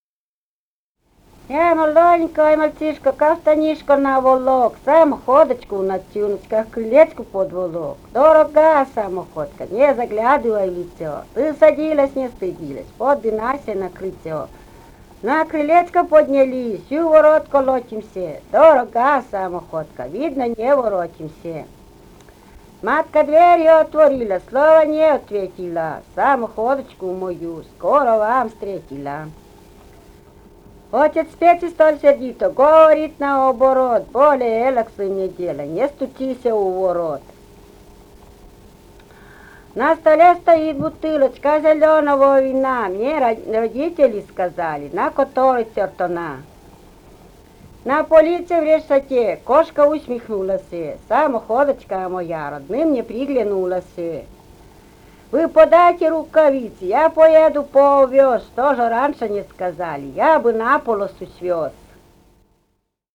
«Я, молоденькой мальчишка» (частушки про самоходку).